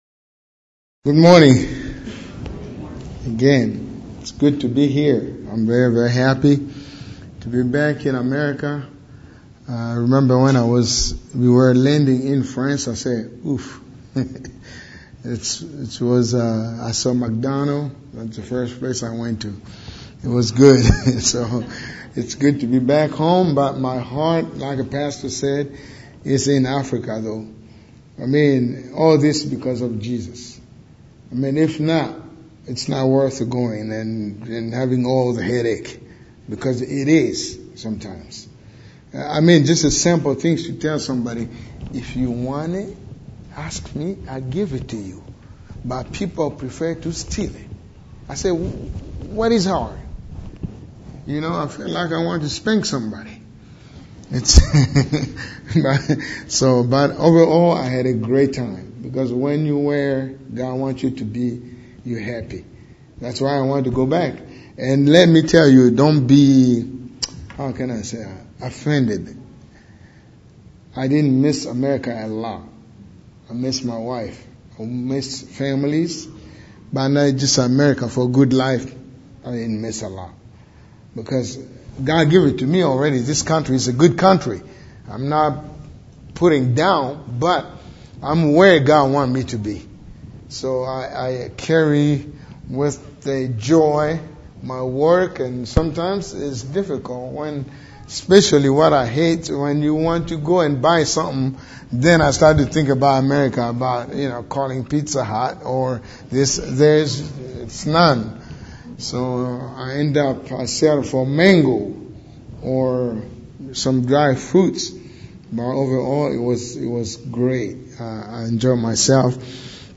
Amos 7 Service Type: Morning Worship « Example of Aged & Younger Men